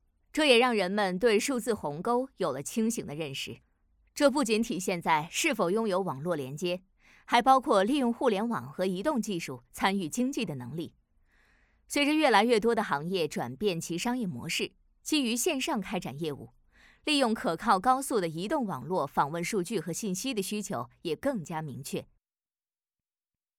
Chinese_Female_047VoiceArtist_2Hours_High_Quality_Voice_Dataset